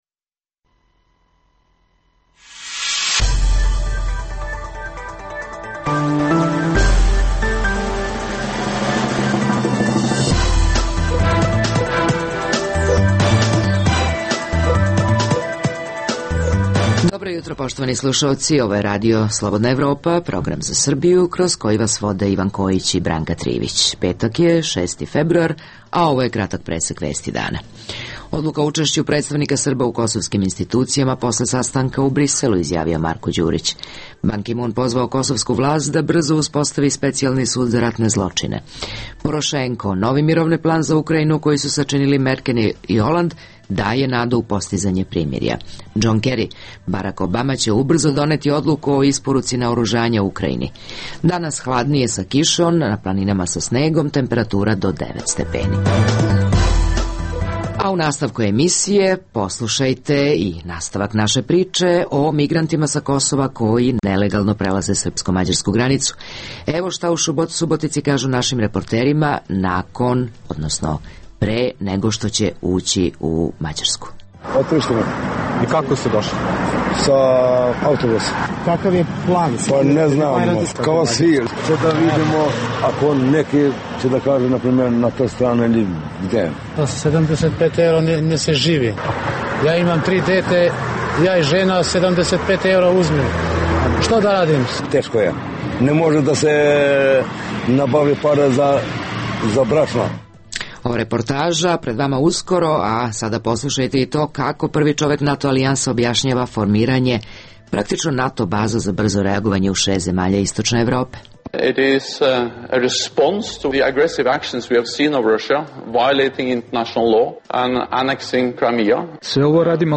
U jutarnjem programu RSE za Srbiju poslušajte reportažu o naglom porastu Kosovara koji ilegalno prelaze srpsko-madjarsku granicu u potrazi za boljim životom na Zapadu.